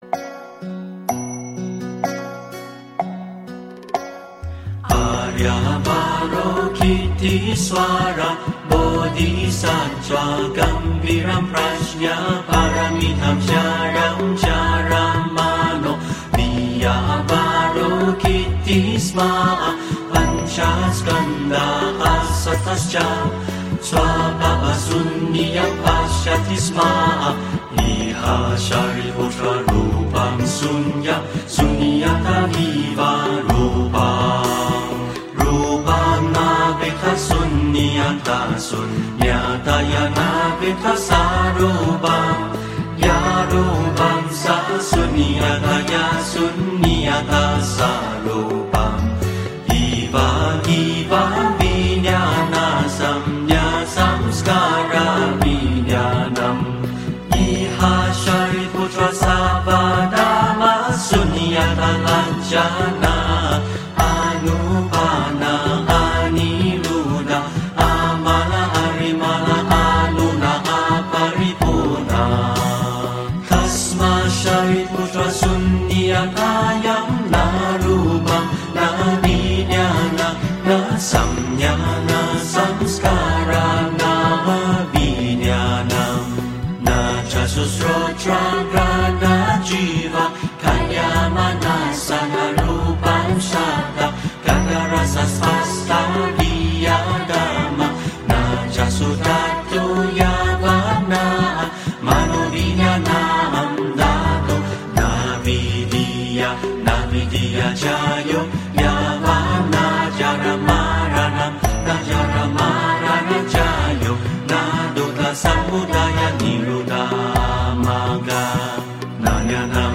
梵语心经 - 诵经 - 云佛论坛
梵语心经 诵经 梵语心经--未知 点我： 标签: 佛音 诵经 佛教音乐 返回列表 上一篇： 心经 下一篇： 十一面观音根本咒 相关文章 普庵咒--未知 普庵咒--未知...